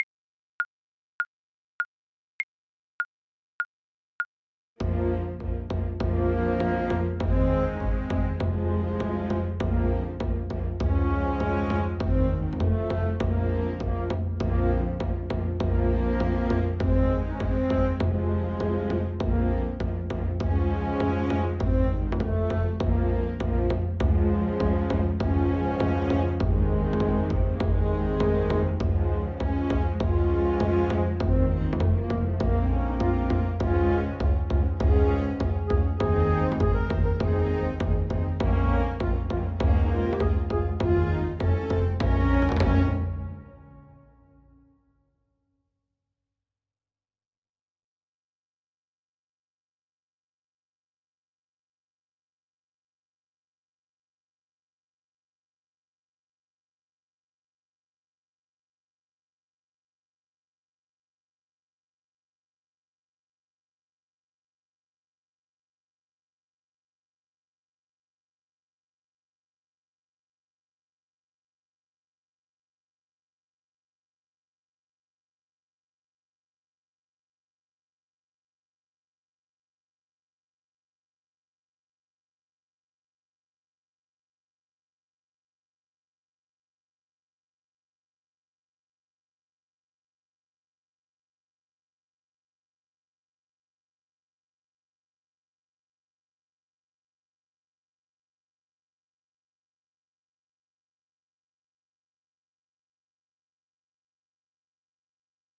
French Horn
4/4 (View more 4/4 Music)
C4-Bb5
Moderato = c. 100
F minor (Sounding Pitch) C minor (French Horn in F) (View more F minor Music for French Horn )
Scottish